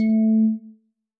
bell
添加三个简单乐器采样包并加载（之后用于替换部分音效）